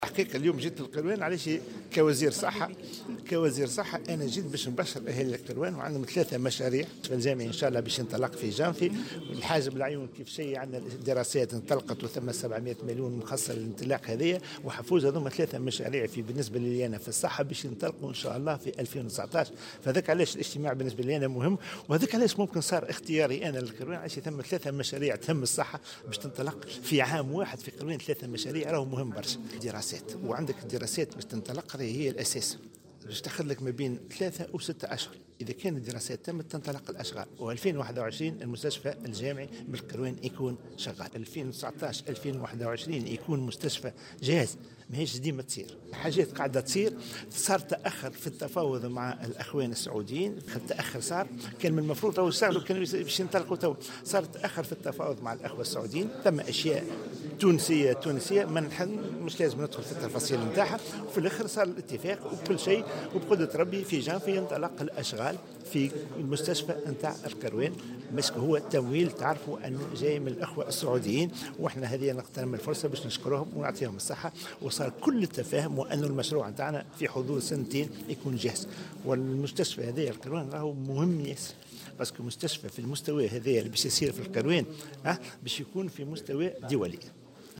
وأضاف في تصريح لمراسل "الجوهرة أف أم" بالجهة أن هذه المشاريع تهم المستشفى الجامعي، الذي من المنتظر أن يدخل حيز الاستغلال خلال سنة 2021، إضافة إلى مستشفى حفوز ومستشفى حاجب العيون الذي انطلقت الدراسات الخاصة به.